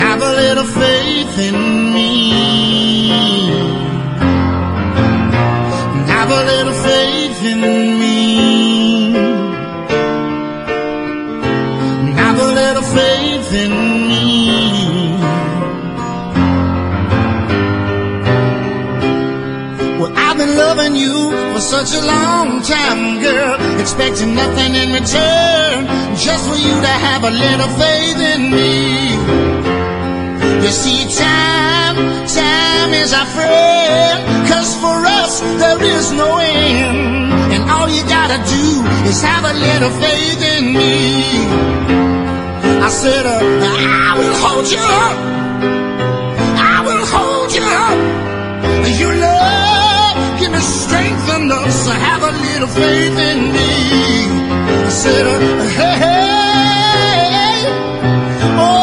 ¥880 (税込) ROCK / 80'S/NEW WAVE.
弾けるポップ感がたまらない！